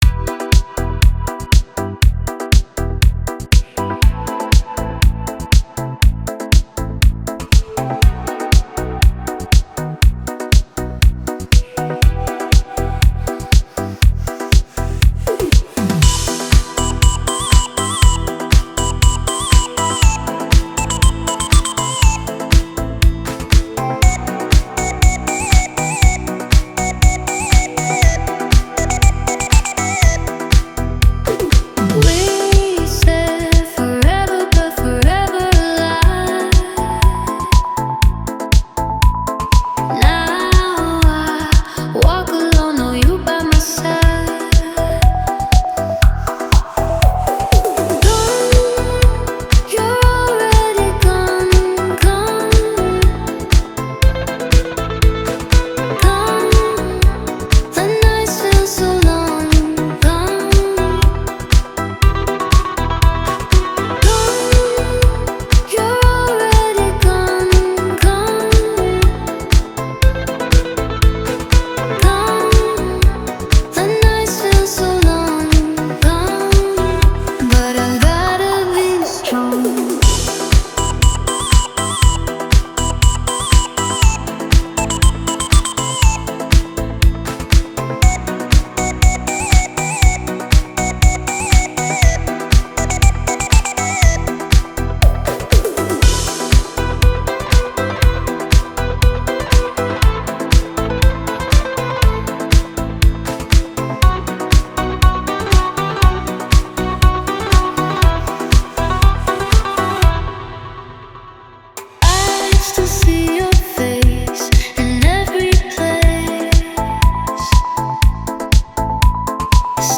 Relaxing Sounds 2025 Top 10 Music Mix